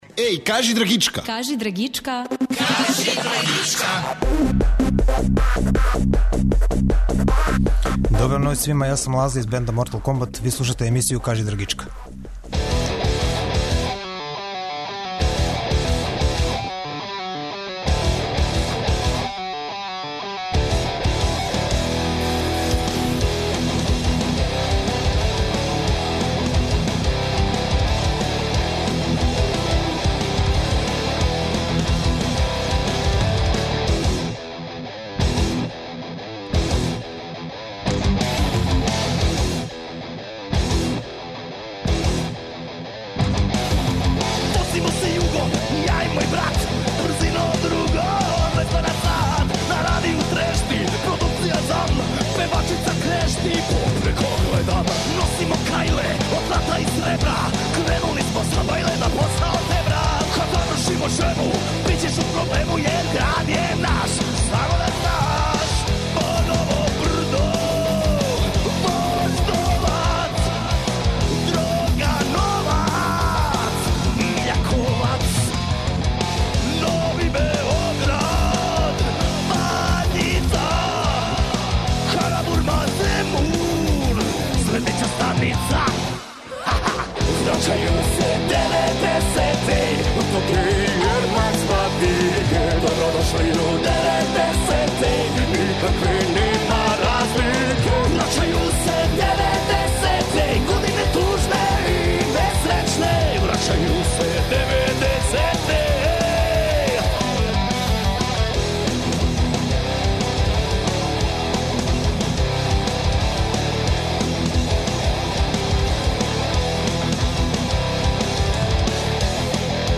Ноћас музику бирају момци из бенда "Мортал Комбат".